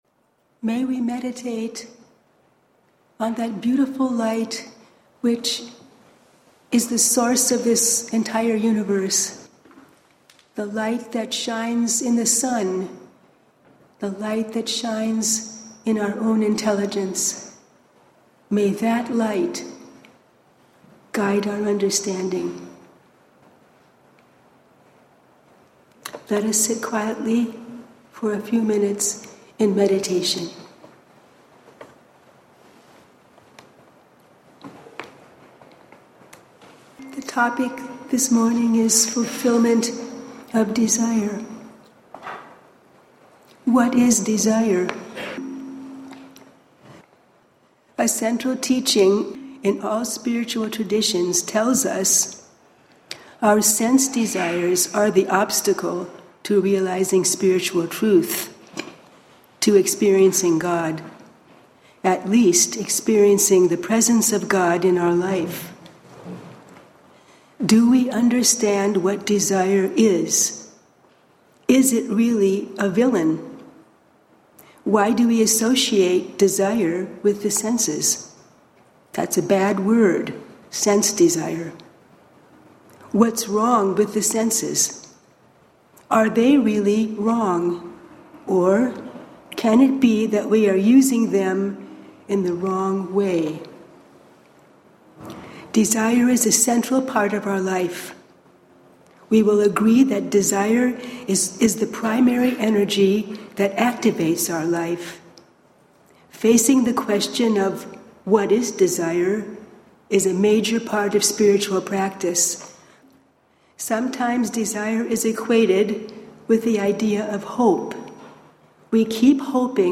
Sunday Lectures March 1